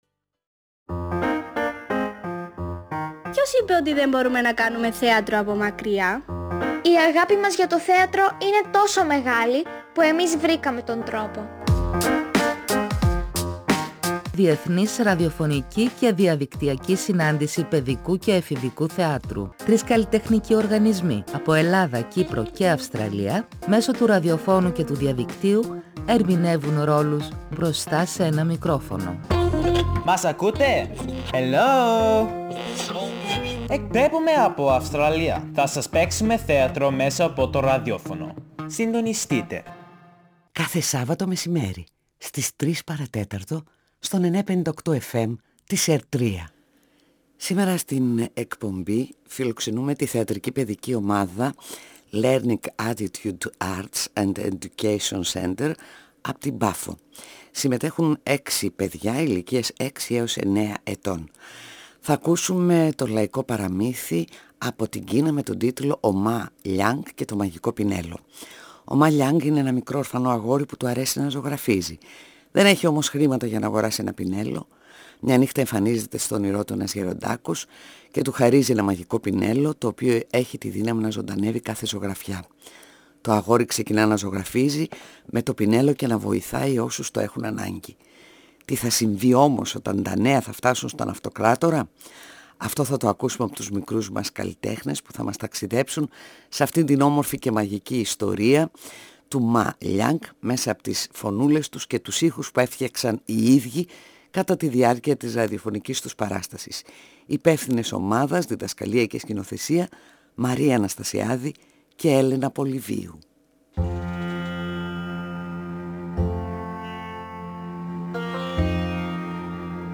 Κάθε Σάββατο στις 2:45 το μεσημέρι, η εκπομπή φιλοξενεί στον 95,8 fm της ΕΡΤ3, την Δεύτερη Διεθνή Ραδιοφωνική Θεατρική σκηνή.